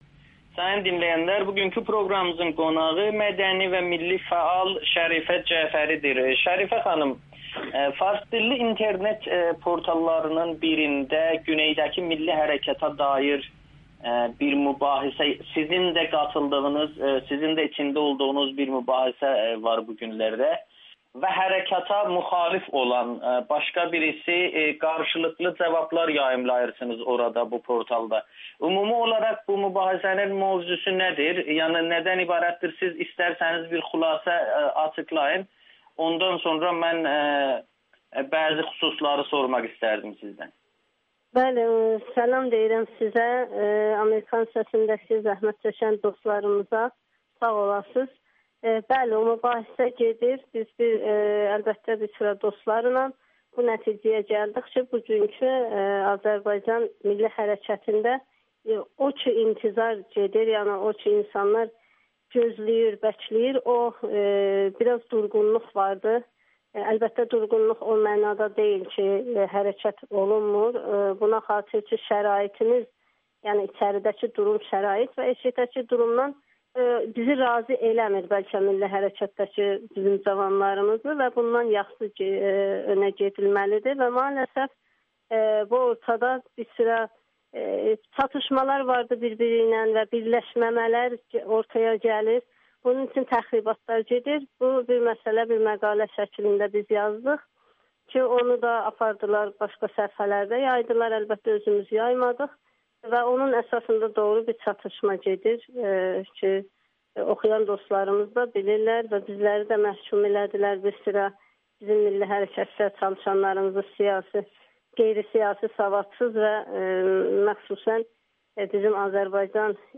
Qadınları hərəkata cəlb etmək önəmlidir [Audio-Müsahibə]